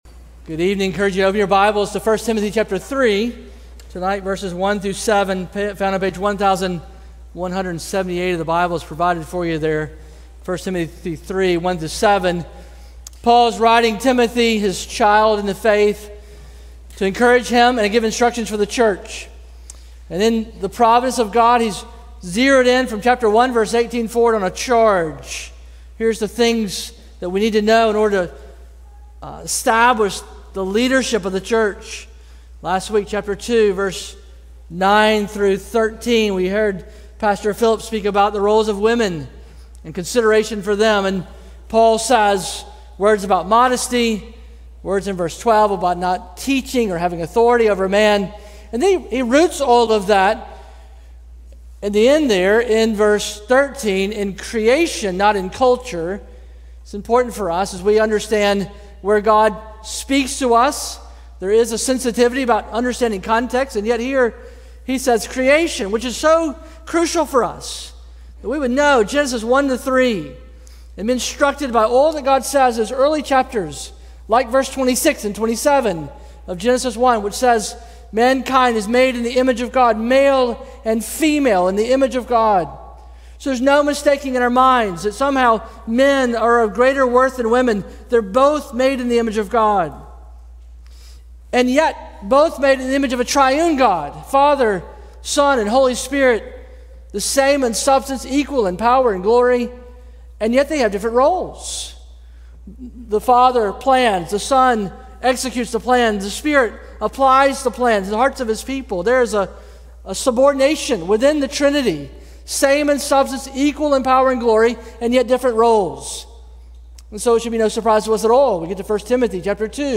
A sermon from the series "Passing on the Faith."